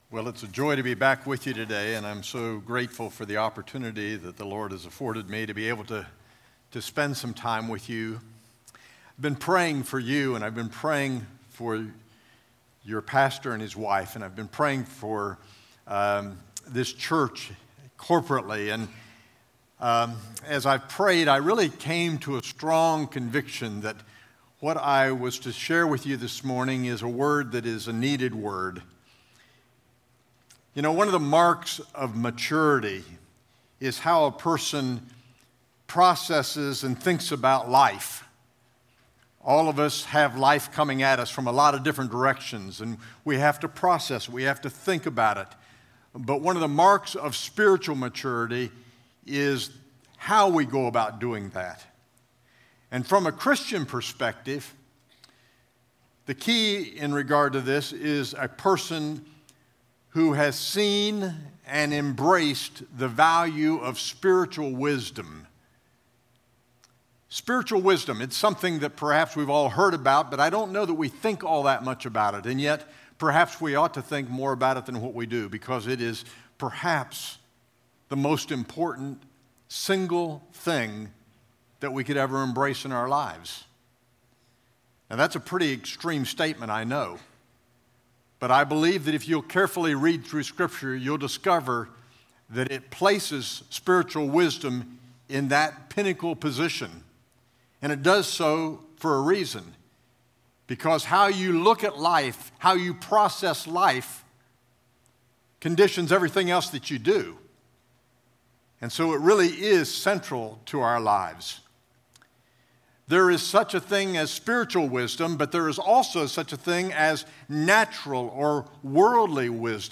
A message from the series "Summer Favorites."